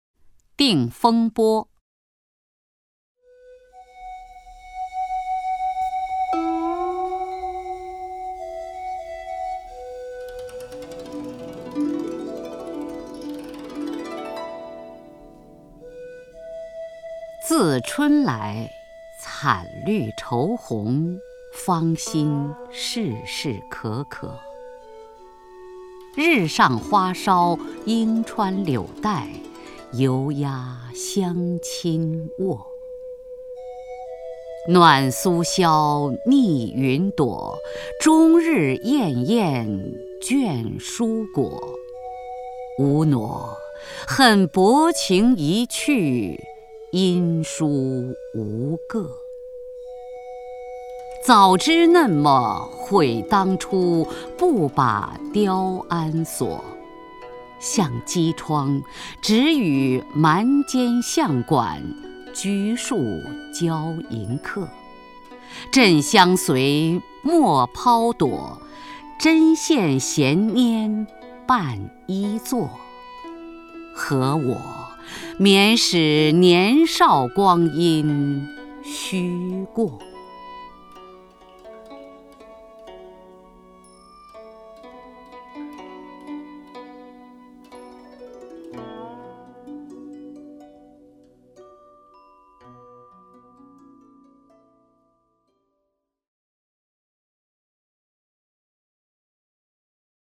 首页 视听 名家朗诵欣赏 雅坤
雅坤朗诵：《定风波·自春来》(（北宋）柳永)
DingFengBoZiChunLai_LiuYong(YaKun).mp3